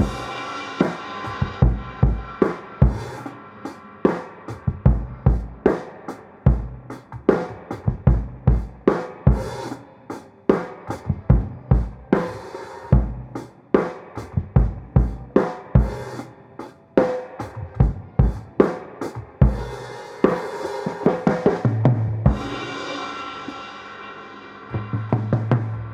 Springfield Break.wav